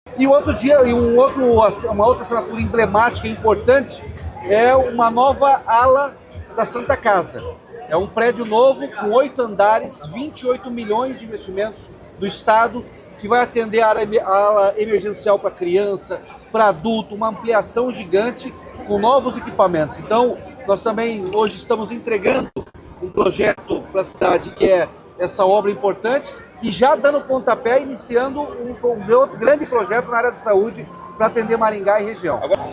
Sonora do governador Ratinho Junior sobre o investimento de R$ 28 milhões para novo prédio da Santa Casa de Maringá